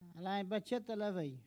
Enquête Arexcpo en Vendée
Catégorie Locution ( parler, expression, langue,... )